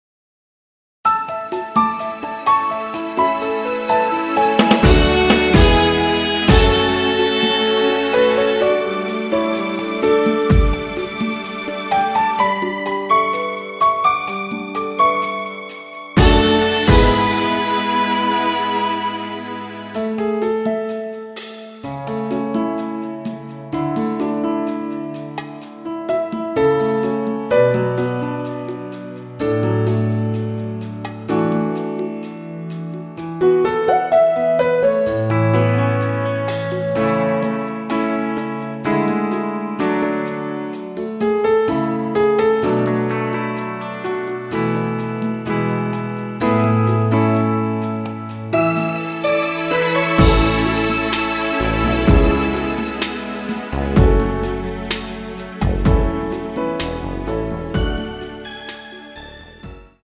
앞부분30초, 뒷부분30초씩 편집해서 올려 드리고 있습니다.
축가 MR